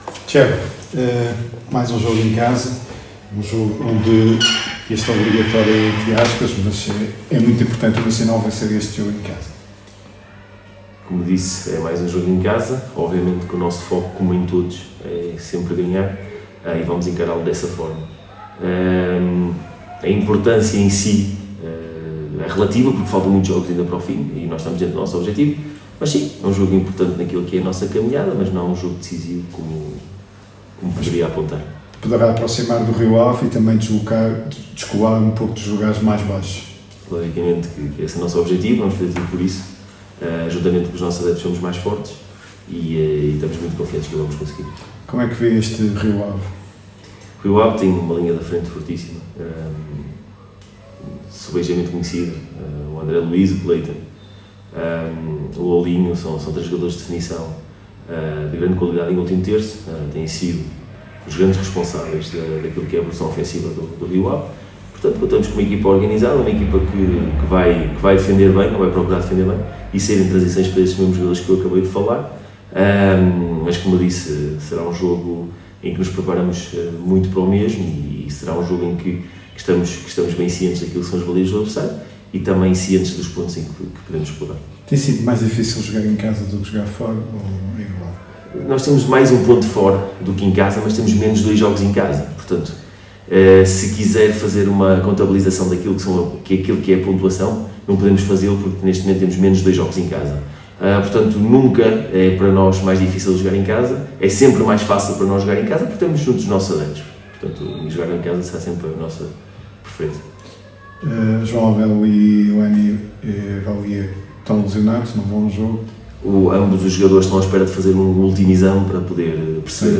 Na conferência de imprensa de antevisão à partida